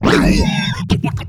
alianhit2.wav